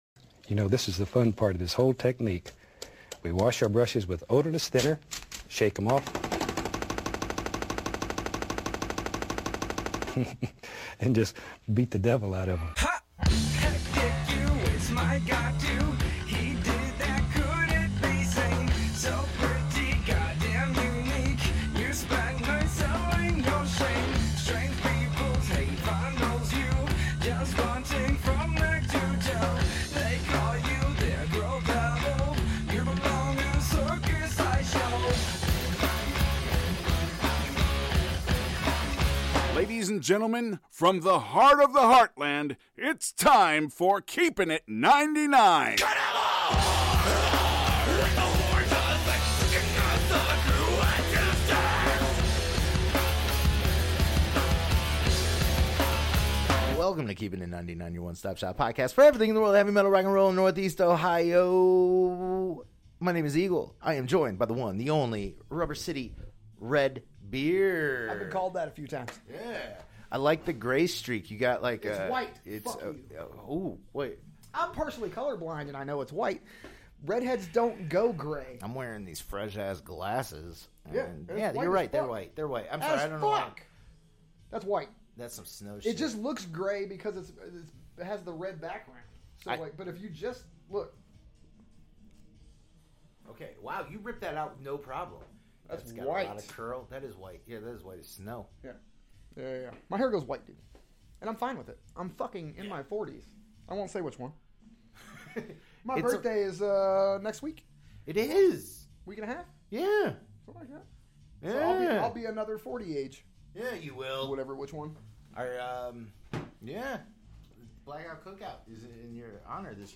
We get together at Assassin Tattoo in Akron